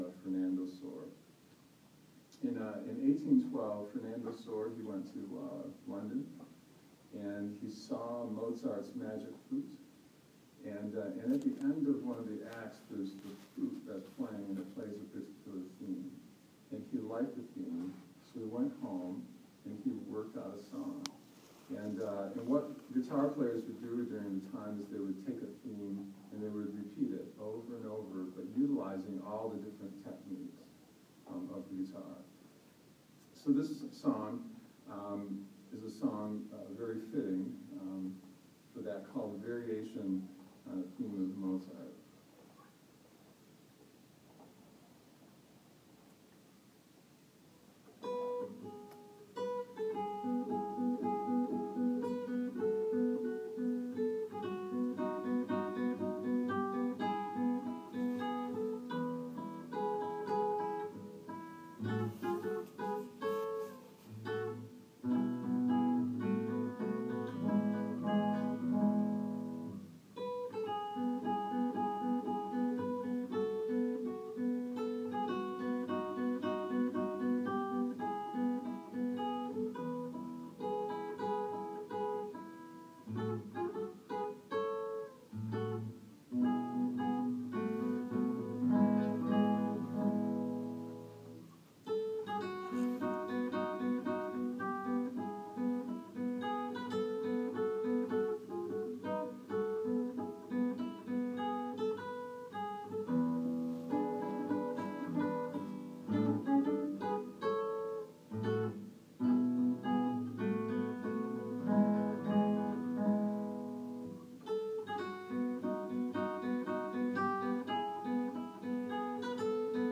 attending a concert at our local library
a classical guitarist